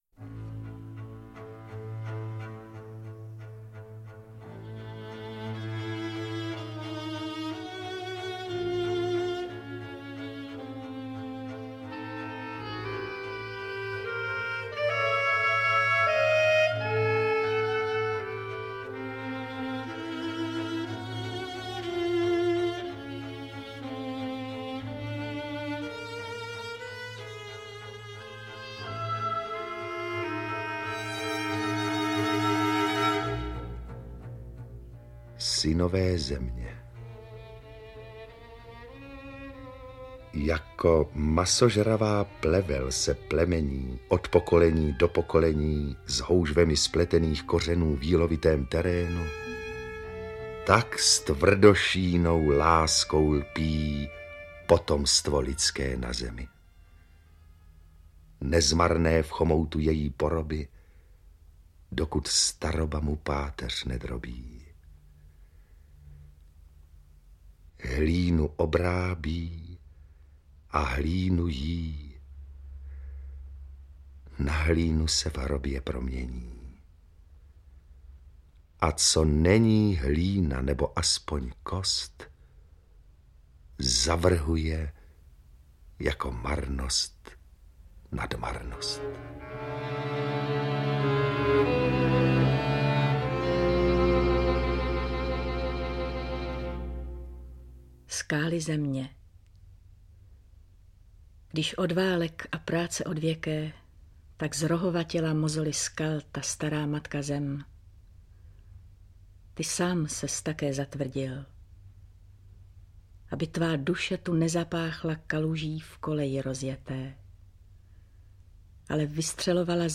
Interpret:  Otakar Brousek st.
beletrie / poezie